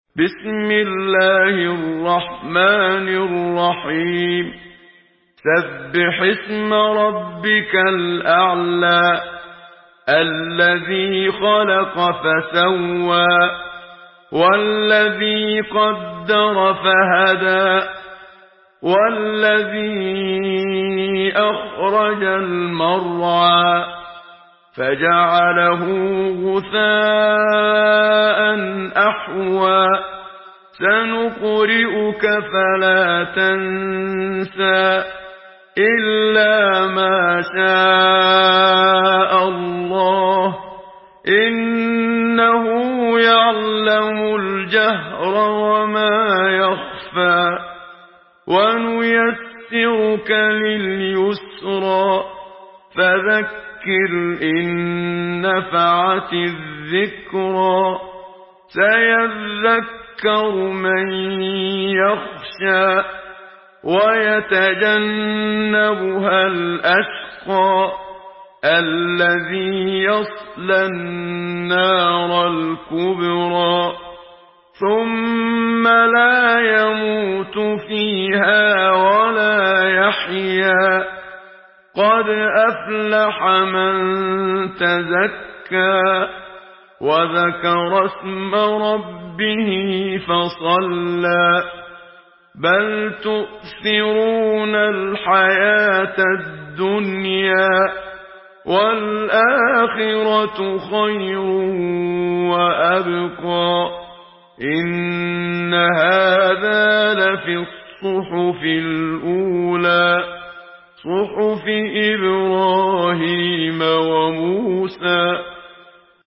Sourate Al-Ala MP3 à la voix de Muhammad Siddiq Minshawi par la narration Hafs
Une récitation touchante et belle des versets coraniques par la narration Hafs An Asim.
Murattal Hafs An Asim